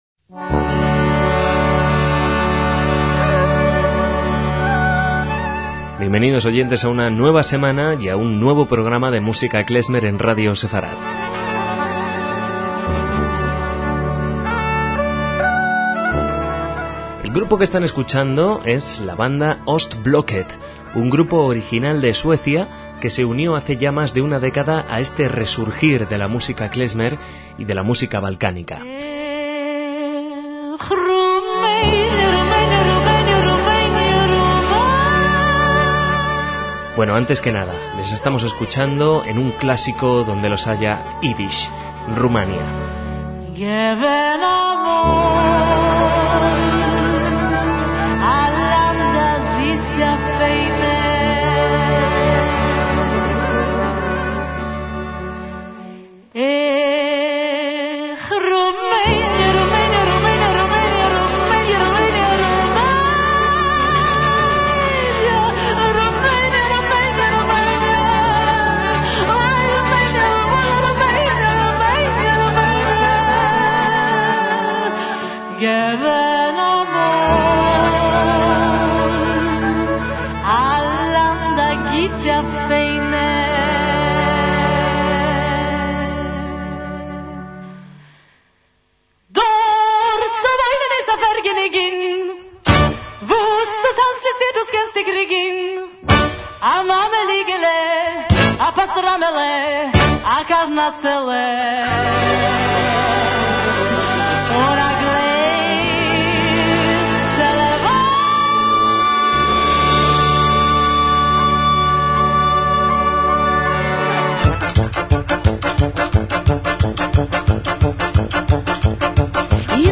MÚSICA KLEZMER
la vocalista
la trompeta
saxo
trombón
eufonio
la tuba
violín
la gaita
la percusión